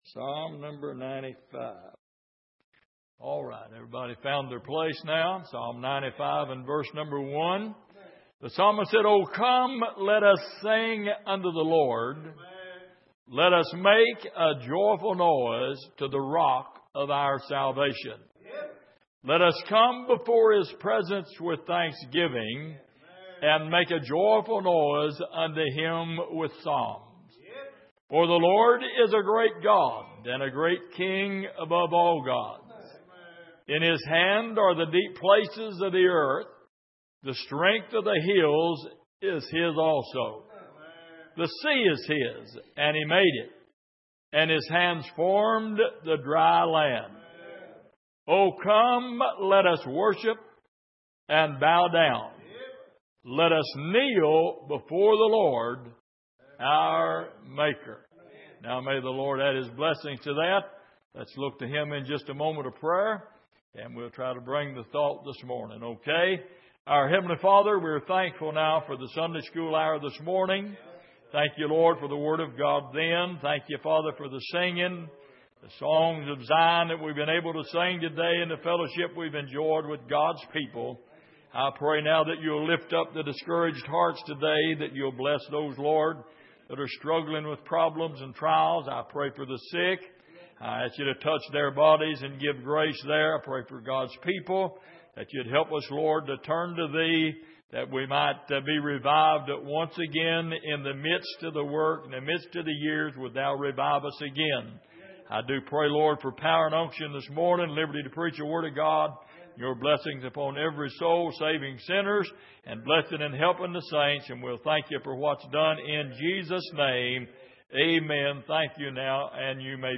Passage: Psalm 95:1-6 Service: Sunday Morning